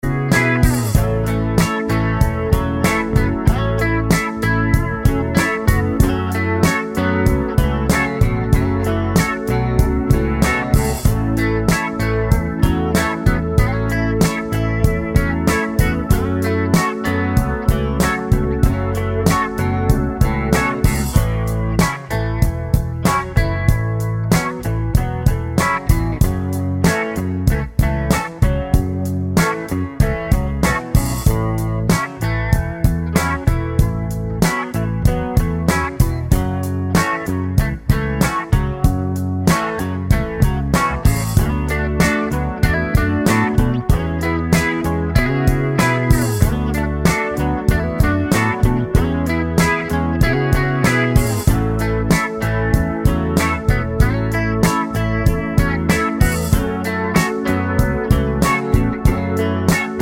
no Backing Vocals Pop (1980s) 3:59 Buy £1.50